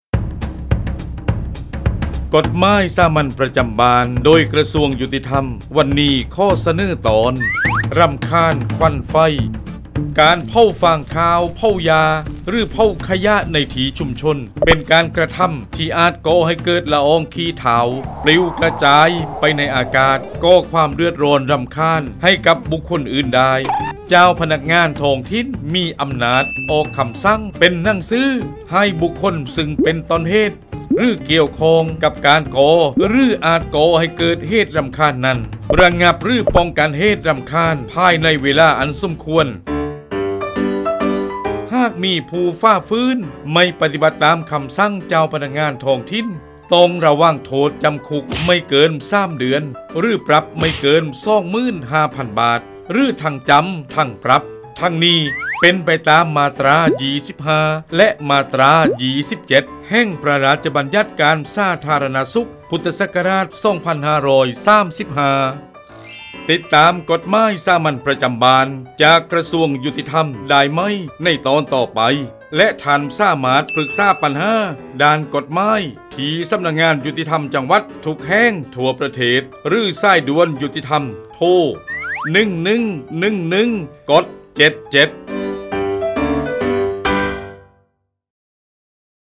กฎหมายสามัญประจำบ้าน ฉบับภาษาท้องถิ่น ภาคใต้ ตอนรำคาญควันไฟ
ลักษณะของสื่อ :   คลิปเสียง, บรรยาย